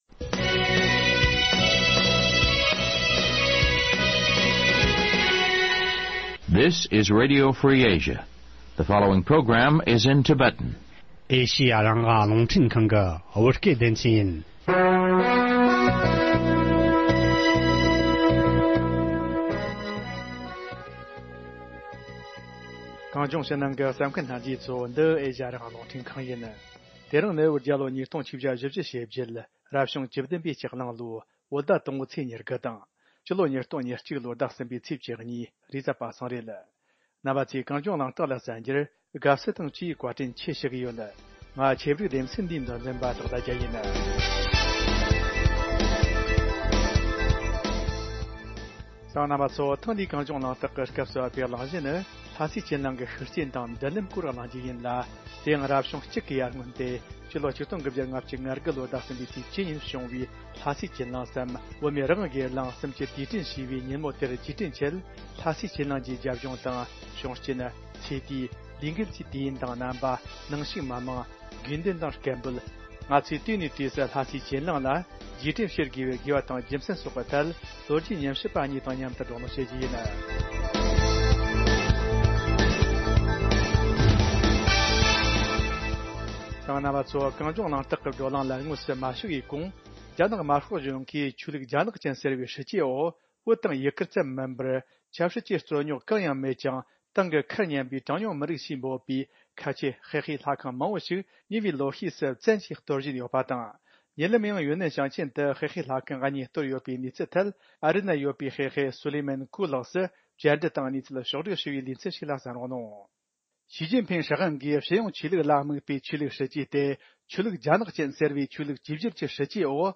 བགྲོ་གླེང